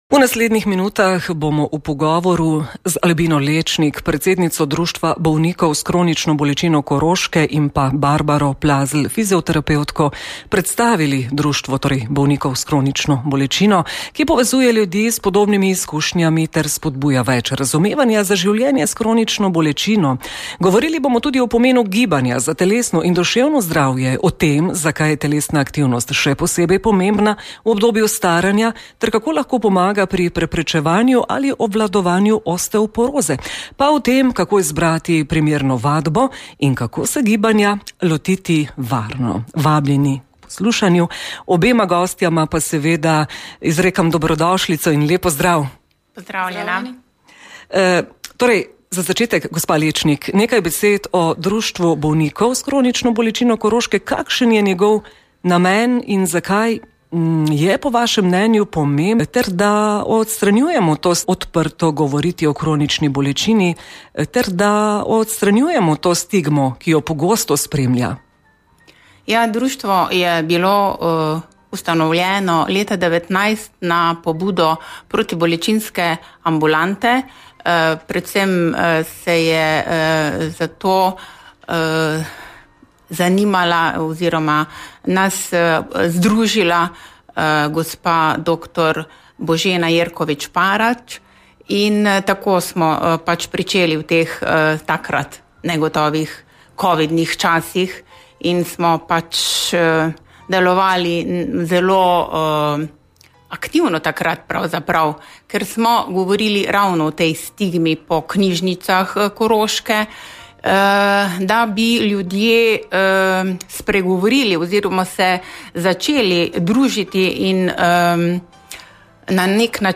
Pogovoru